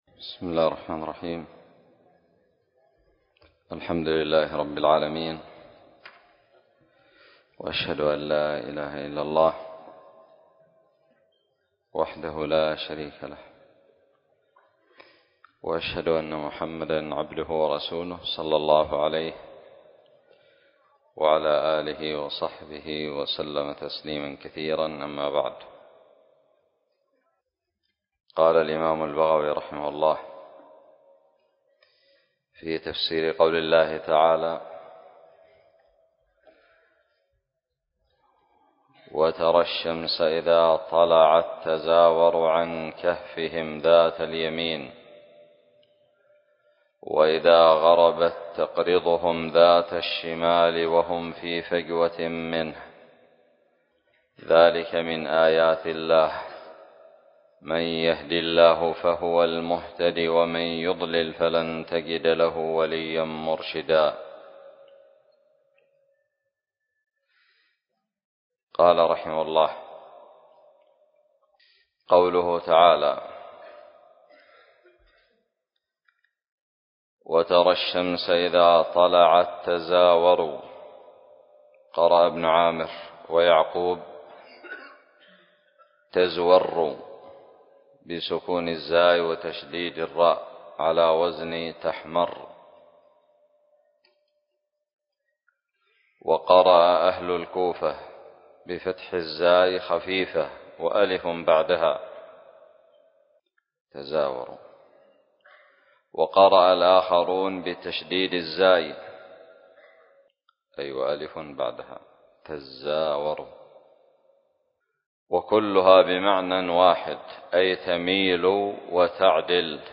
17 الدرس الخامس من تفسير سورة الكهف من تفسير البغوي آية
ألقيت بدار الحديث السلفية للعلوم الشرعية بالضالع